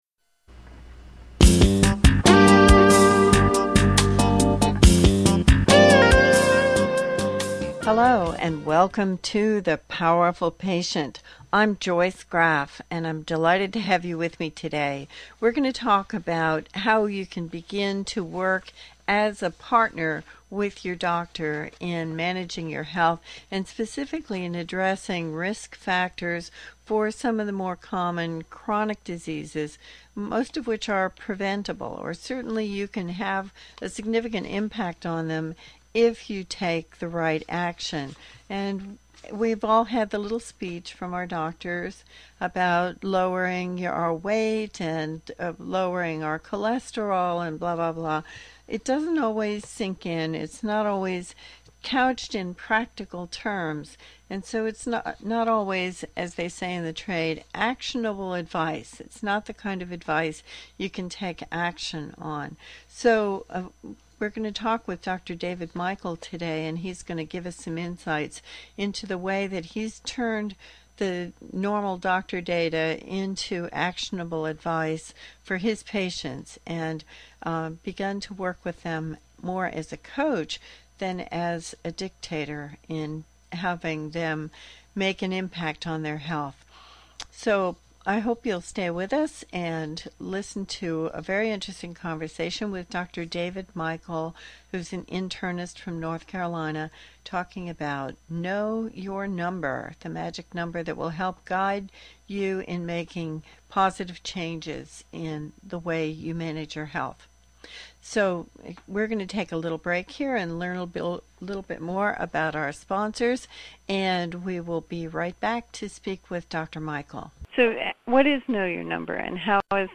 Very little is spent on prevention. We speak with a North Carolina internist who is using a new risk assessment tool to motivate his patients to make constructive changes to prevent such diseases.